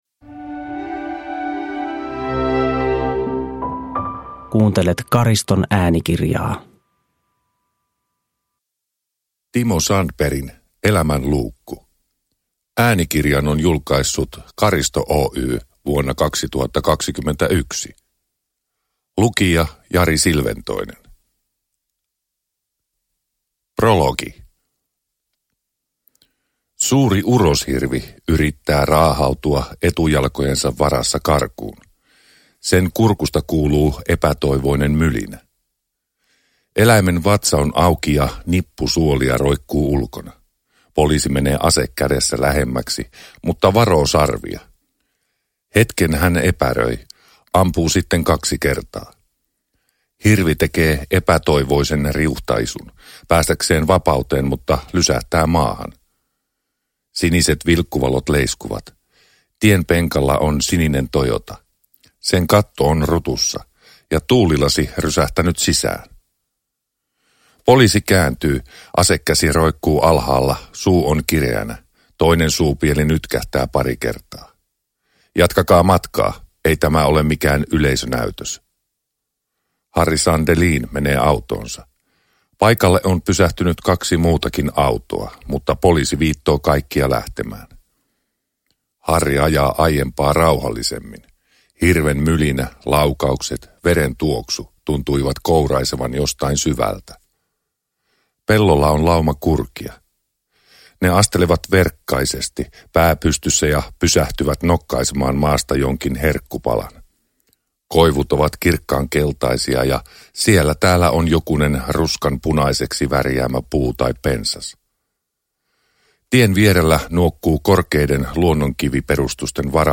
Elämänluukku – Ljudbok – Laddas ner